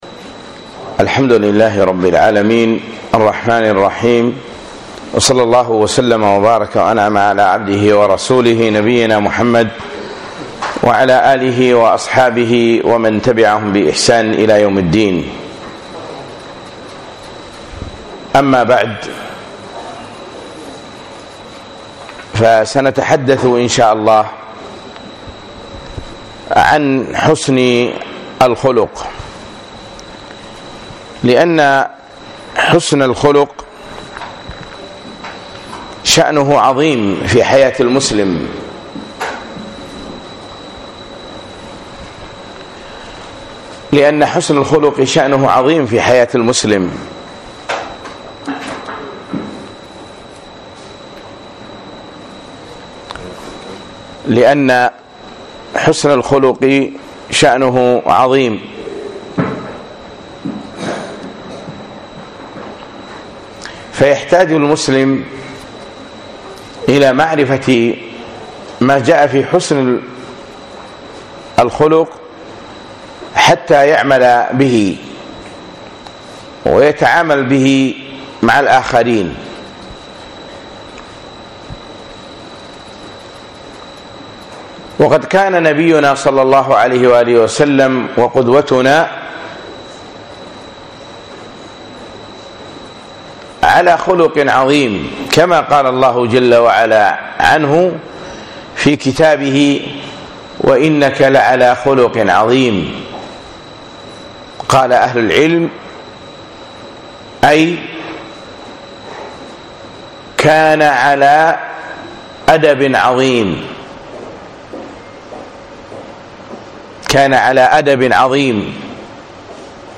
حسن الخلق - محاضرة بدولة الكويت